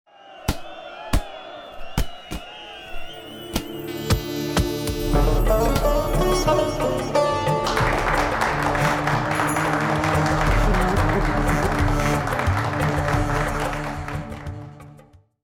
Es existiert meist unerkannt, man kann es nicht sehen, anfassen oder riechen - man kann es nur hören: Das Geräusch der Woche. Wir vertonen ein Ereignis der letzten sieben Tage.